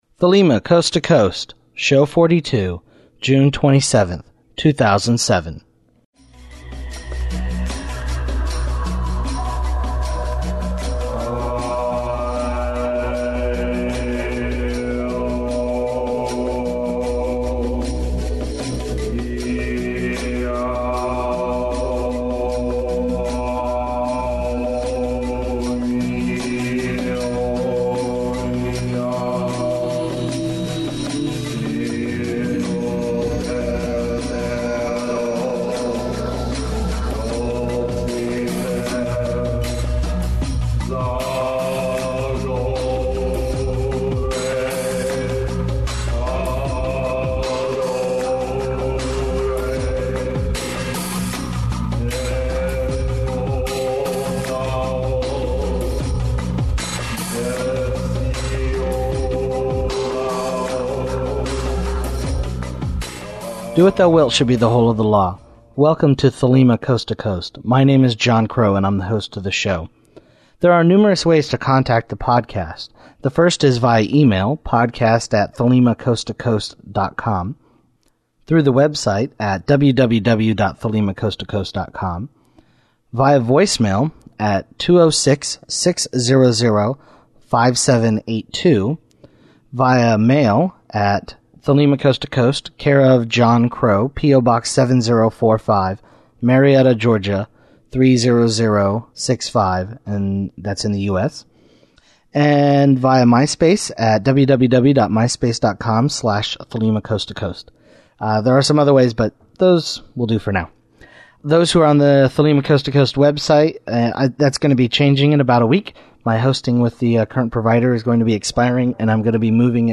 Listener feedback Interview